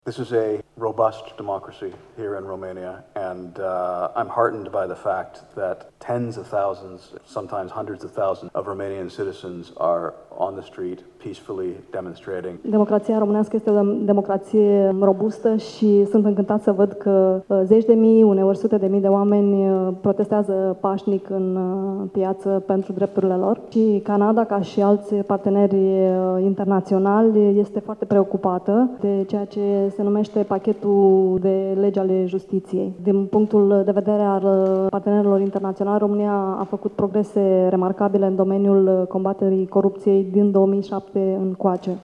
Afirmaţia a fost făcută astăzi, la Iaşi, de ambasadorul Canadei în România, Excelenţa Sa, Kevin Hamilton: